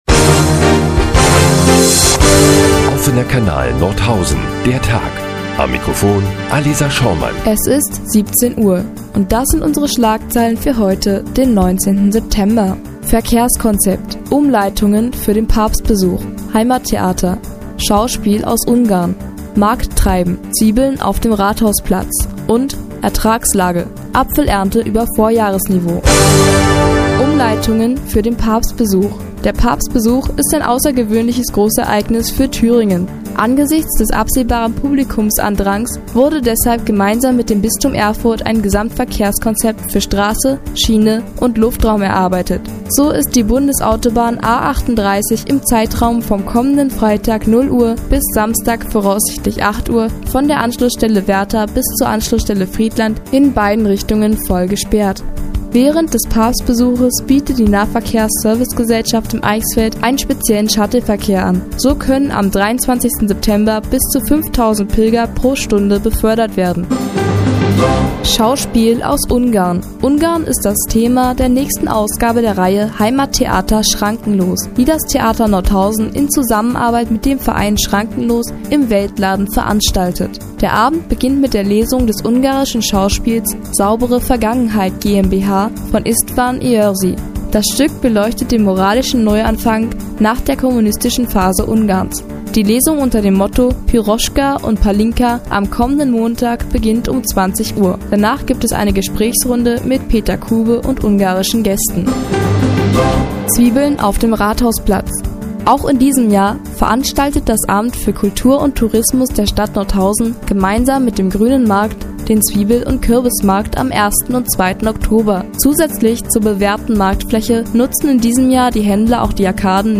19.09.2011, 17:00 Uhr : Seit Jahren kooperieren die nnz und der Offene Kanal Nordhausen. Die tägliche Nachrichtensendung des OKN ist nun auch in der nnz zu hören.